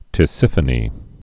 (tĭ-sĭfə-nē)